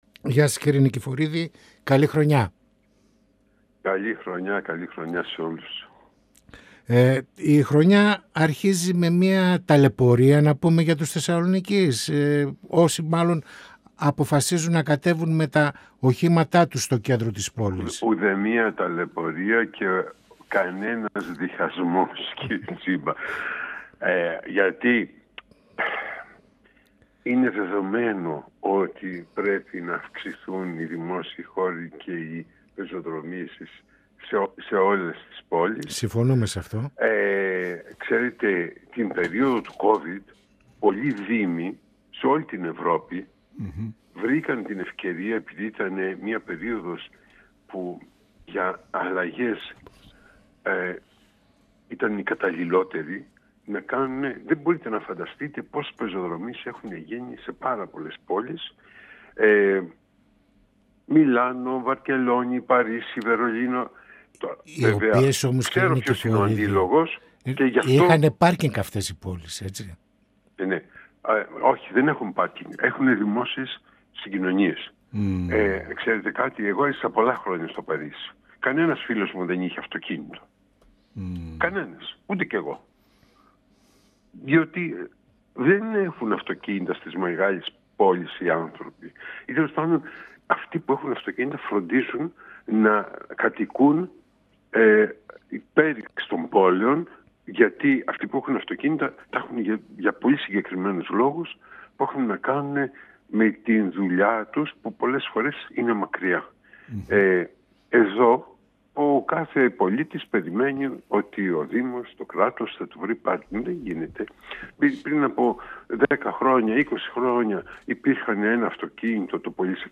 Στις αναπλάσεις που αλλάζουν το δημόσιο χώρο στη Θεσσαλονίκη αναφέρθηκε ο Αντιδήμαρχος Τεχνικών Έργων και Βιώσιμης Κινητικότητας του Δήμου Θεσσαλονίκης Πρόδρομος Νικηφορίδης, μιλώντας στην εκπομπή «Πανόραμα Επικαιρότητας» του 102FM της ΕΡΤ3.